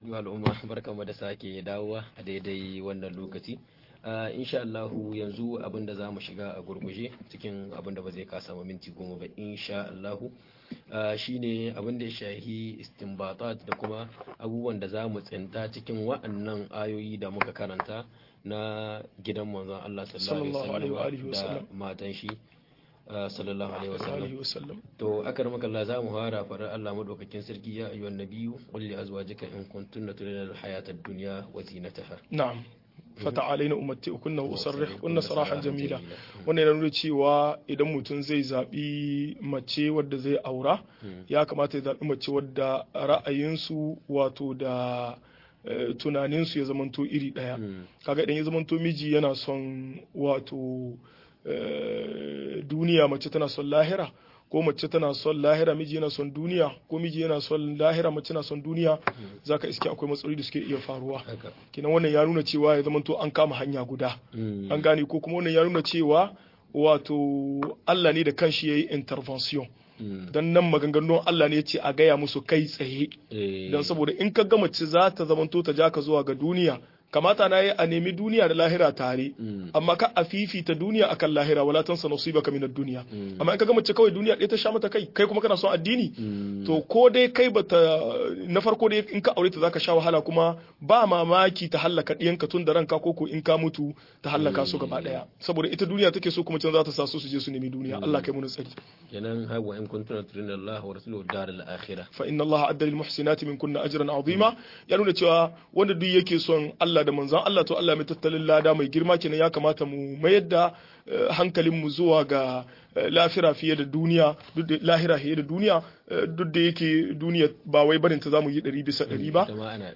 Yadda ake karatun ilimin tarihi - MUHADARA